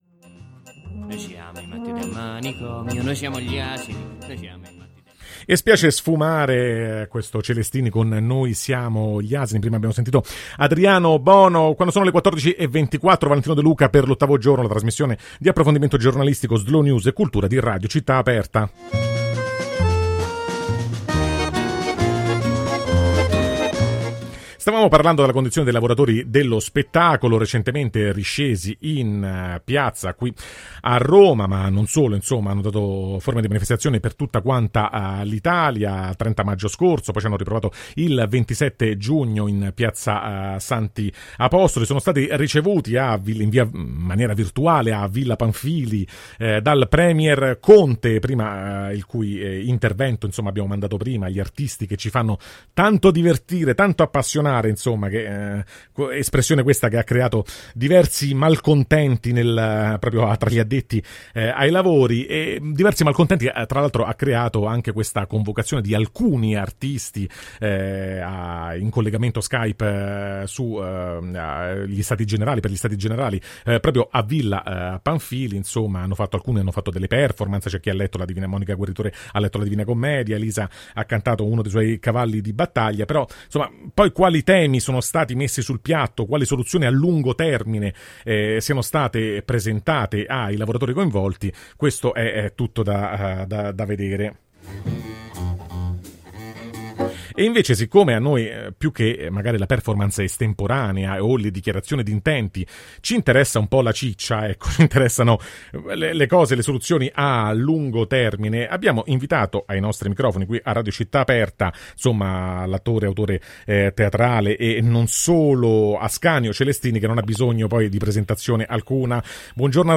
“Americà, facce Tarzan” – Perchè dobbiamo cambiare atteggiamento quando parliamo di lavoro nello spettacolo [Intervista ad Ascanio Celestini] | Radio Città Aperta